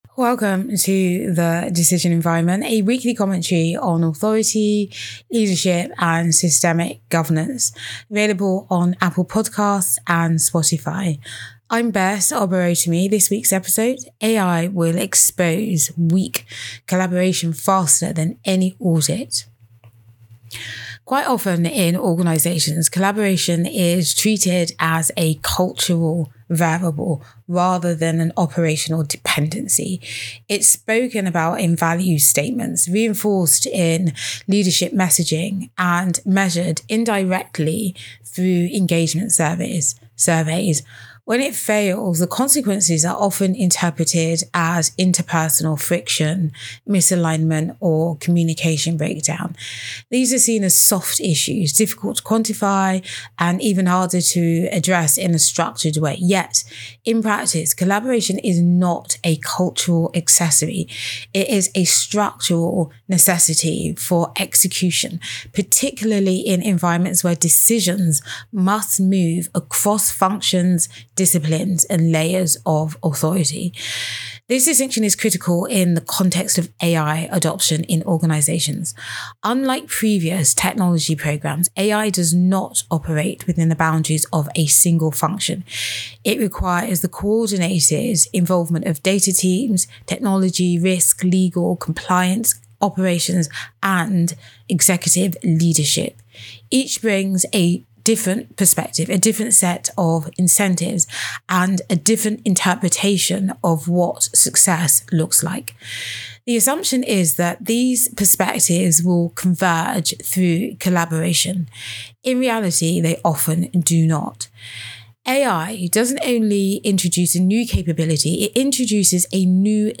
Audio Commentary AI governance collaboration is becoming the real test of whether AI adoption in organisations delivers results.